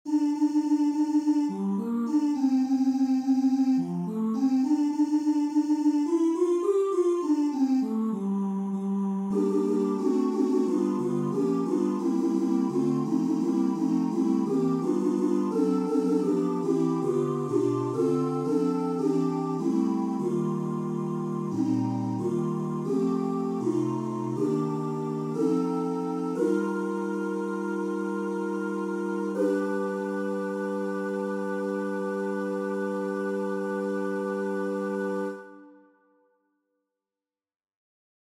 Key written in: G Dorian
How many parts: 4
Type: SATB
All Parts mix: